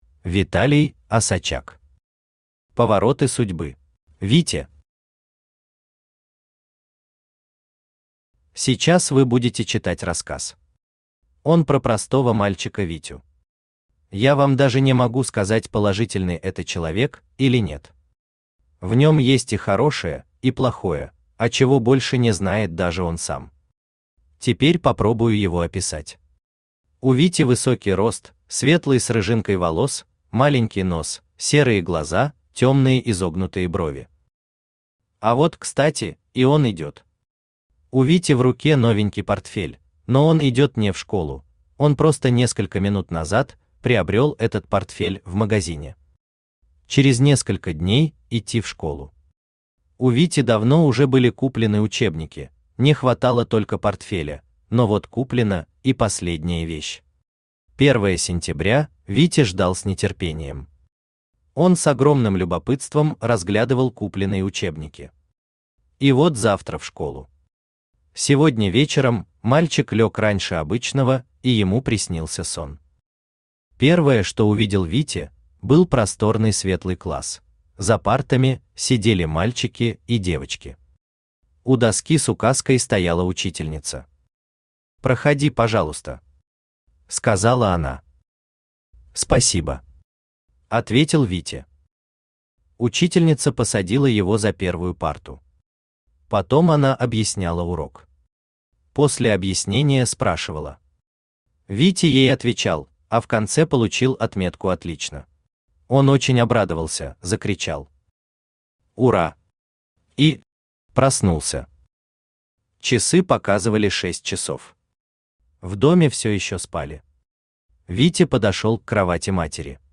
Аудиокнига Повороты судьбы | Библиотека аудиокниг
Aудиокнига Повороты судьбы Автор Vitaly Osadchuk Читает аудиокнигу Авточтец ЛитРес.